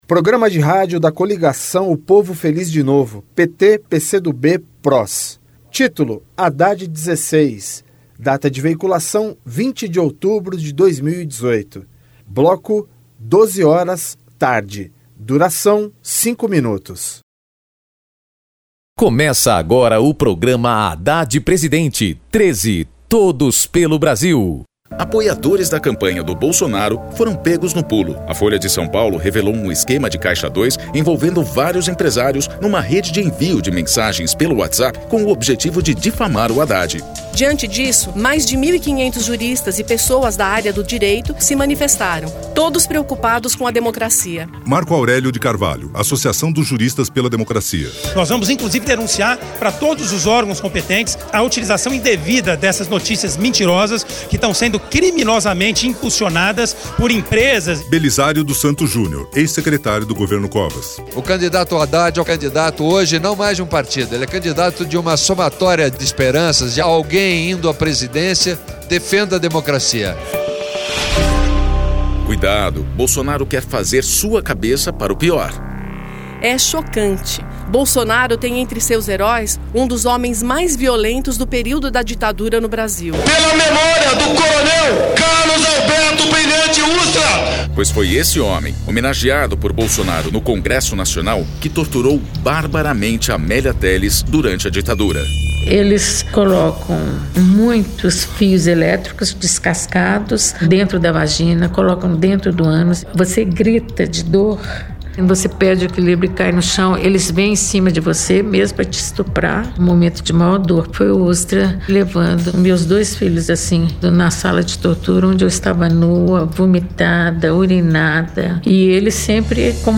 Gênero documentaldocumento sonoro
Descrição Programa de rádio da campanha de 2018 (edição 46), 2º Turno, 20/10/2018, bloco 12hrs.